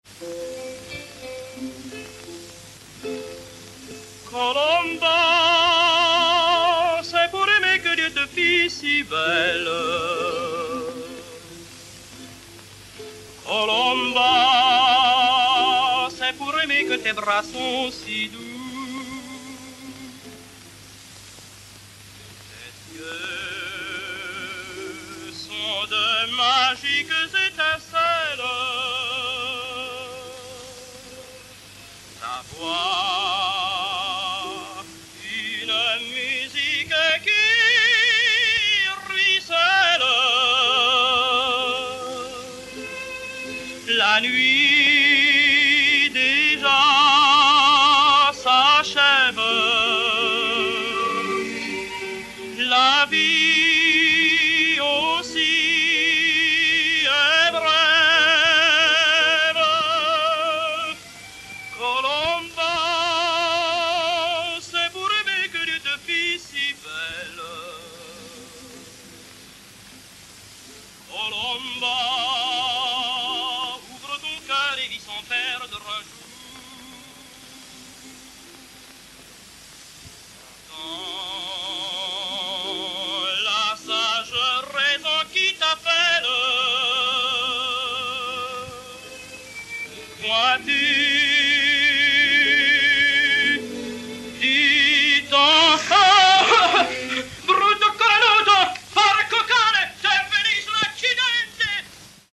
Sérénade